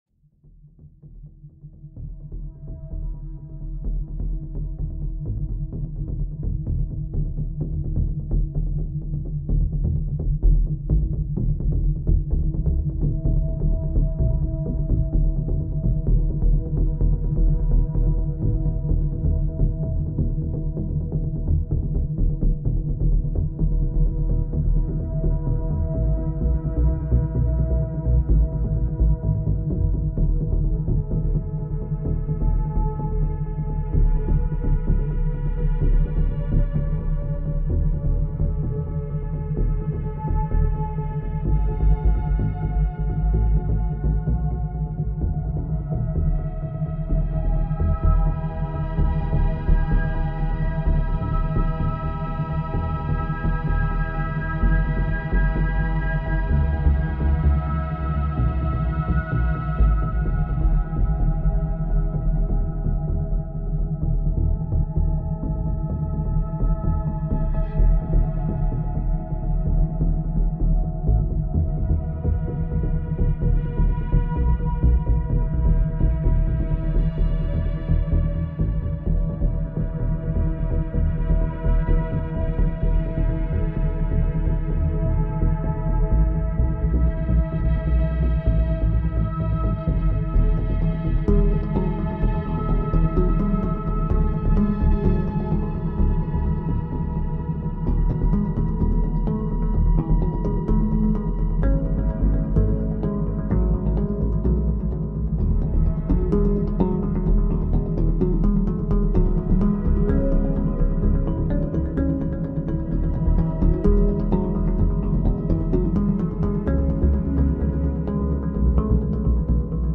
Mental Energizer – Monaural Beats to Improve Focus and Energy